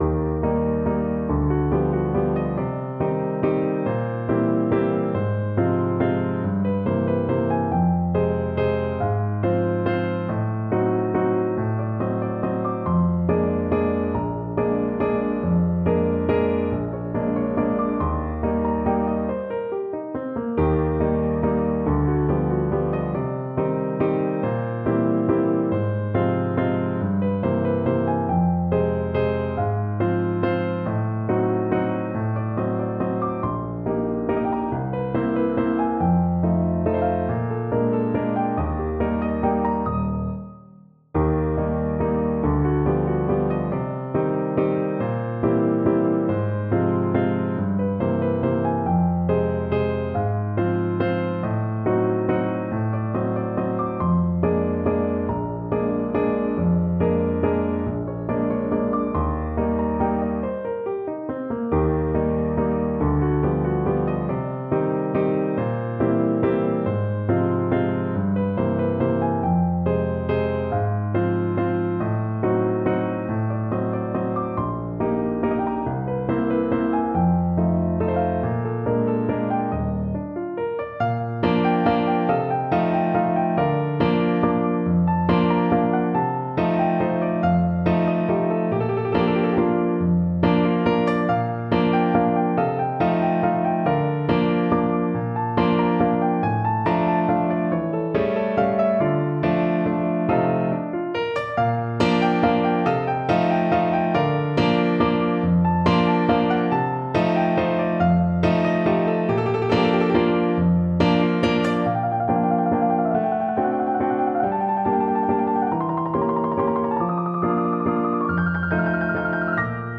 Free Sheet music for Piano
No parts available for this pieces as it is for solo piano.
3/4 (View more 3/4 Music)
Eb major (Sounding Pitch) (View more Eb major Music for Piano )
[Waltz, one in a bar] = 140
Brazilian
coracao_que_sentePNO.mp3